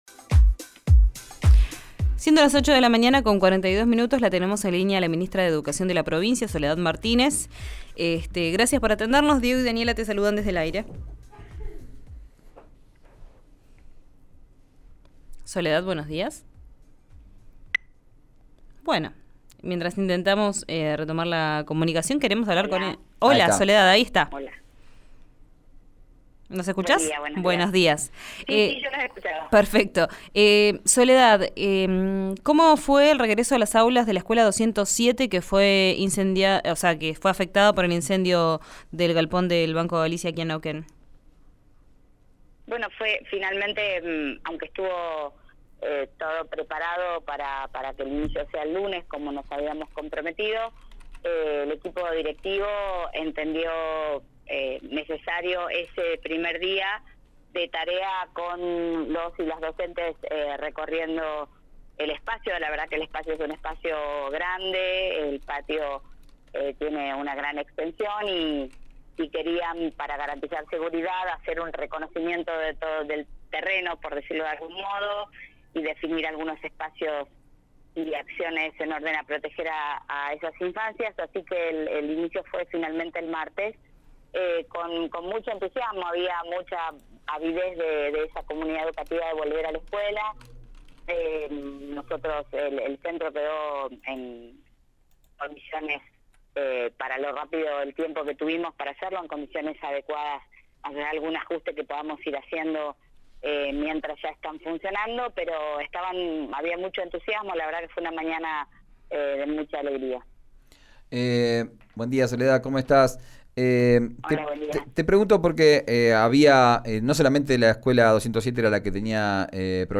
Escuchá a la ministra de Educación de Neuquén, Soledad Martínez, en RÍO NEGRO RADIO: